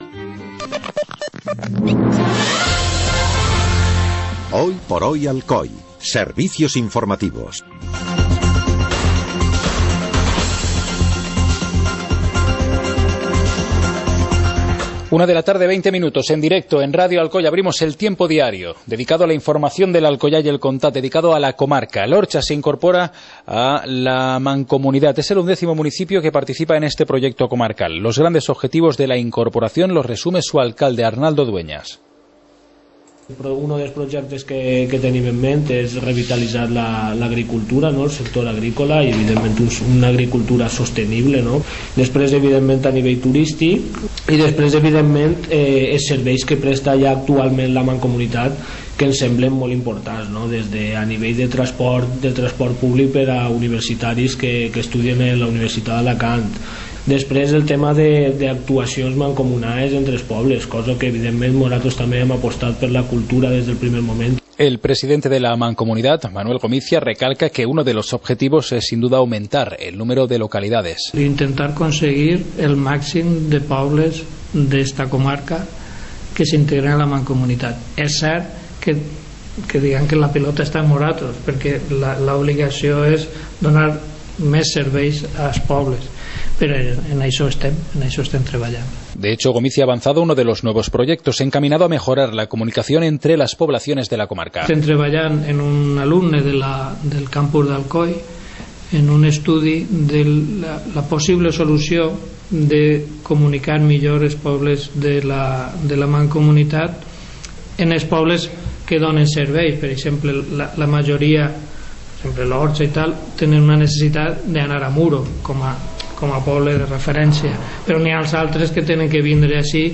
Informativo comarcal - martes, 19 de enero de 2016